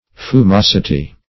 Search Result for " fumosity" : The Collaborative International Dictionary of English v.0.48: Fumosity \Fu*mos"i*ty\, n. [Cf. OF. fumosit['e].]